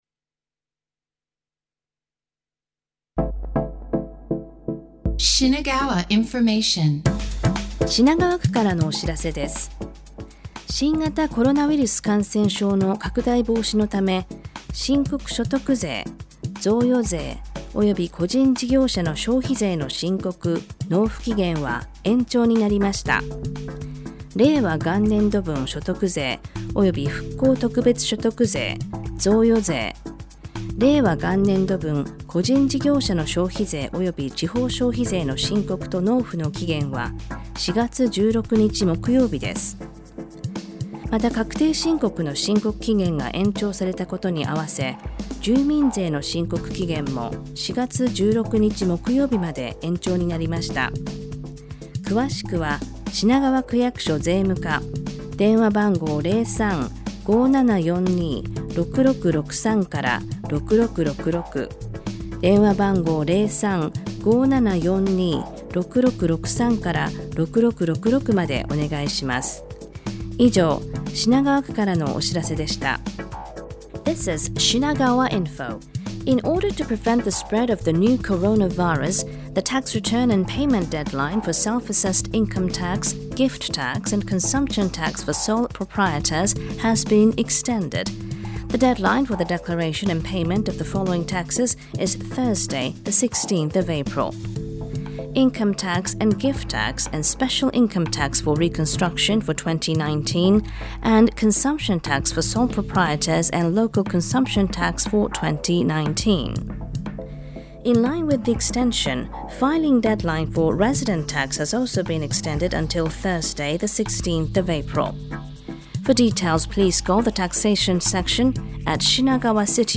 9th/10th April 2020 ON AIR: Information on the the tax return extension  (The deadline has been extended to prevent the spread of the new Coronavirus)